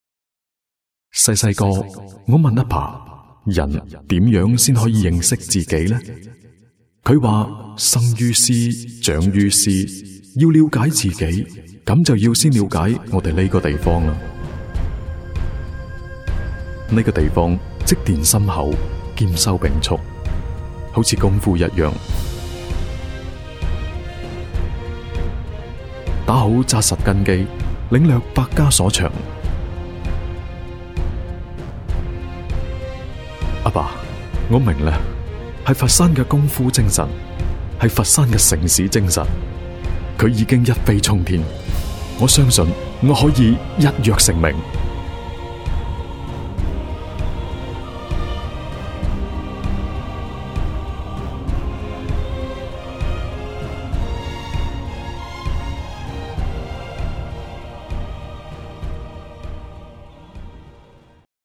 • 男粤37 宣传片-年轻-写实-功夫电影周-粤语广式港式 沉稳|娓娓道来|积极向上